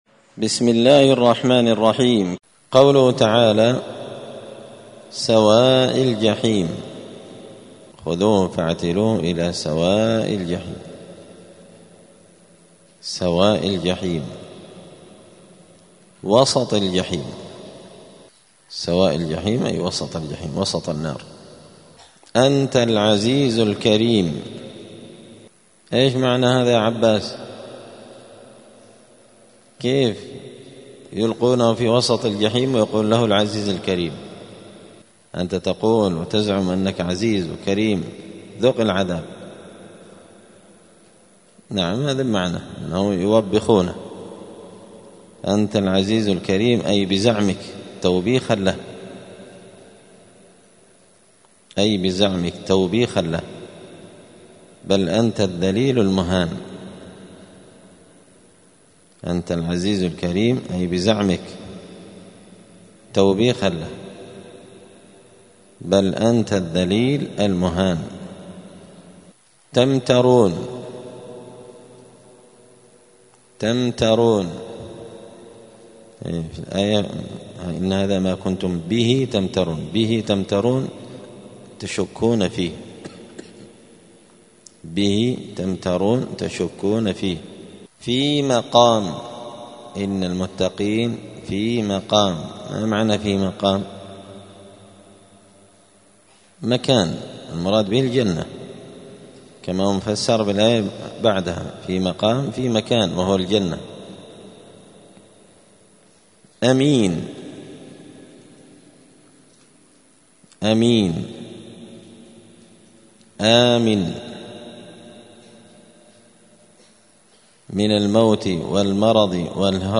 الأثنين 11 شعبان 1446 هــــ | الدروس، دروس القران وعلومة، زبدة الأقوال في غريب كلام المتعال | شارك بتعليقك | 30 المشاهدات
دار الحديث السلفية بمسجد الفرقان قشن المهرة اليمن